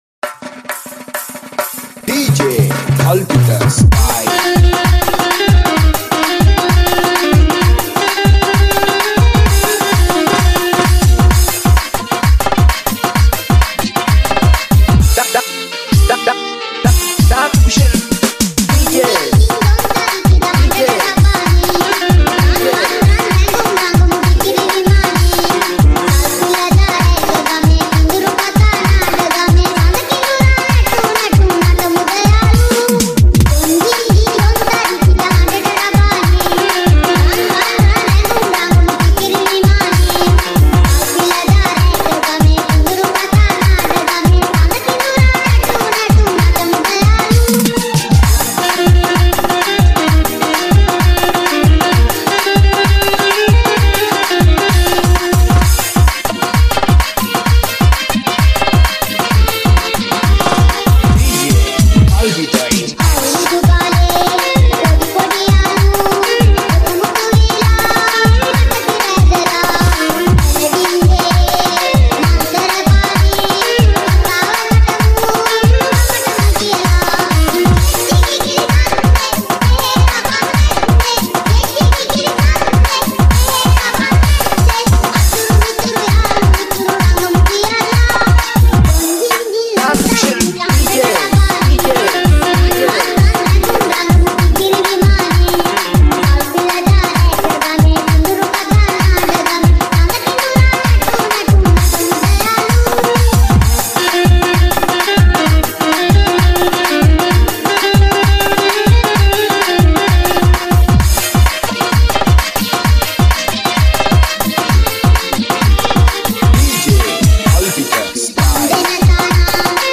Sinhala Dj Remix